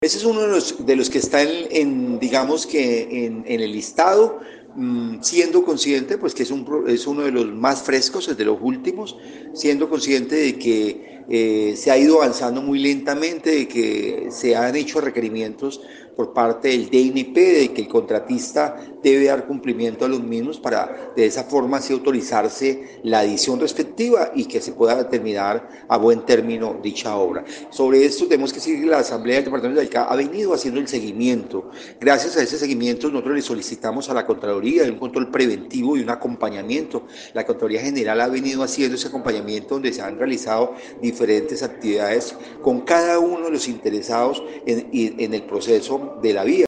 Richard Gutiérrez Cruz, diputado por Cambio Radical y actual presidente de la corporación, dijo que, este es uno de los de mayor impacto social y urbanístico con serios reparos, tanto así que ya ha sido objeto de sesiones especiales de control político en la anterior administración departamental.
DIPUTADO_RICHARD_GUTIERREZ_OBRAS_-_copia.mp3